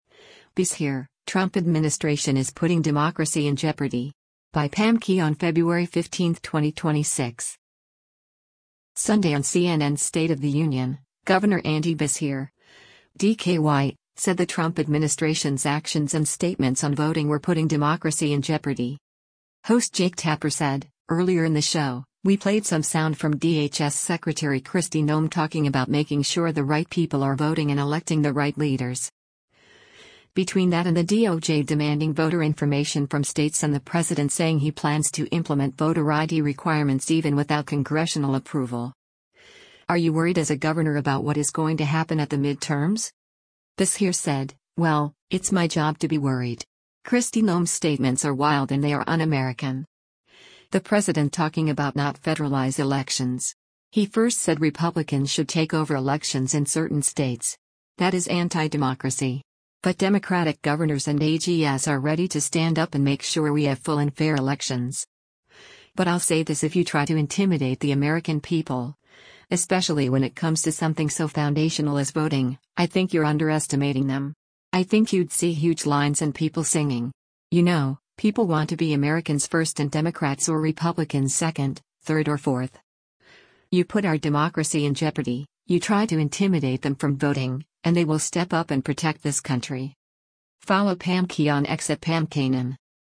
Sunday on CNN’s “State of the Union,” Gov. Andy Beshear (D-KY) said the Trump administration’s actions and statements on voting were putting “democracy in jeopardy.”